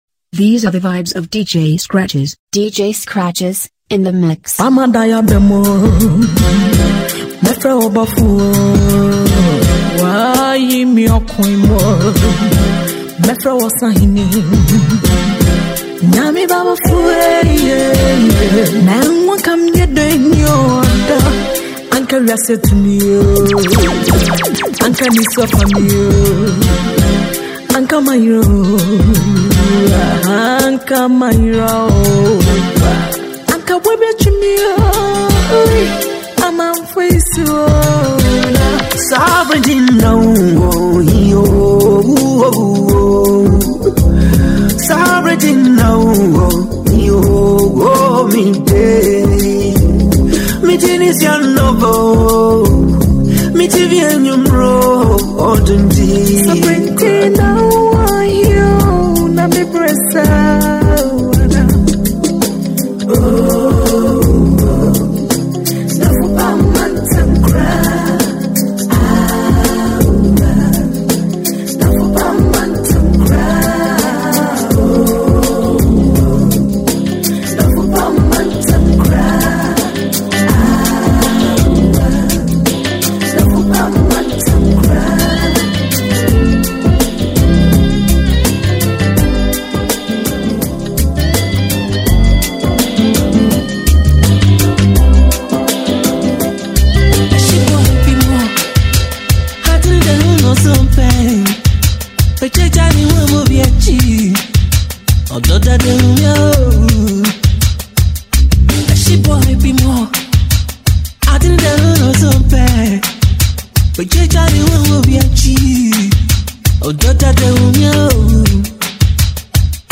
featuring all the song highlife tunes